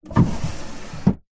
На этой странице собраны уникальные звуки комода: скрипы ящиков, стук дерева, движение механизмов.
Звук задвигаемого ящика в комоде